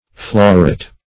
Floret \Flo"ret\, n. [OF. florete, F. fleurette, dim. of OF.